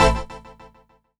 54_21_organ-A.wav